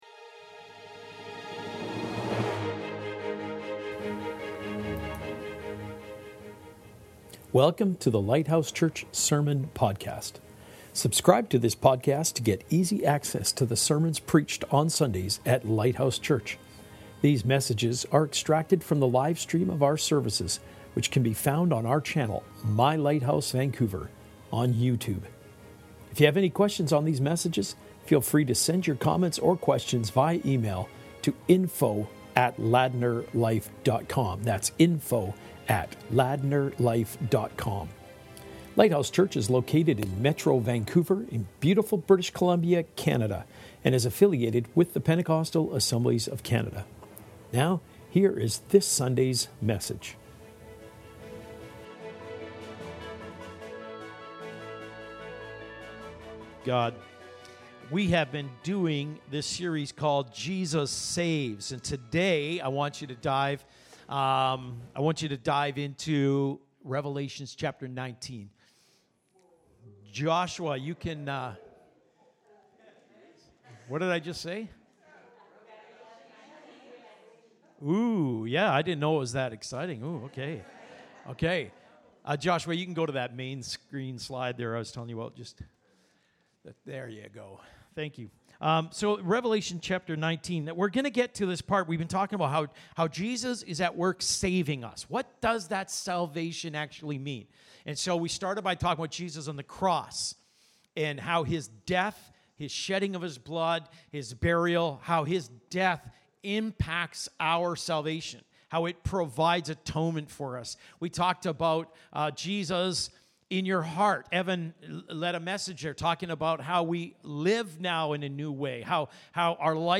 Fully Current Sermon Christ on the Whitehorse Jesus Saves....